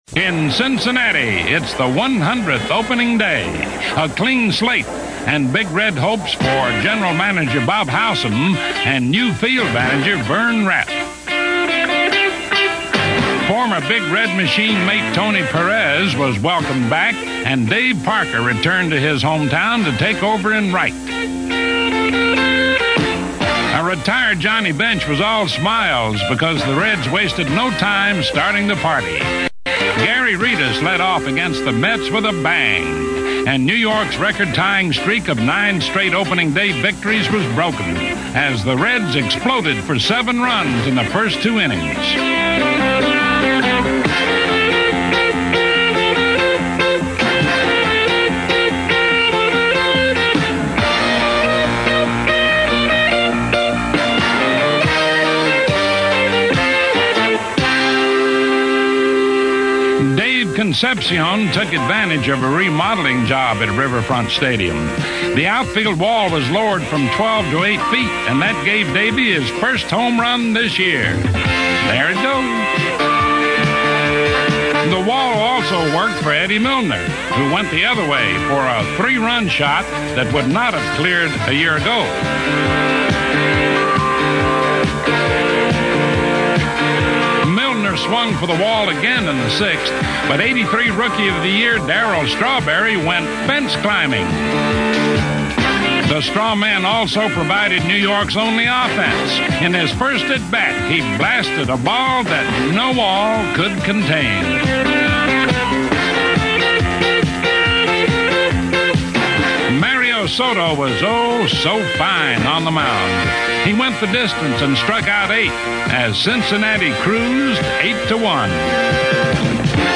For millions of fans across the 1940s, 50s, and especially the 1960s, that voice belonged to Mel Allen, the warm, rolling baritone who narrated baseball’s greatest moments with a storyteller’s grace and a fan’s heart.
But even as a boy, he possessed a natural musicality in his speech — a cadence that hinted at destiny.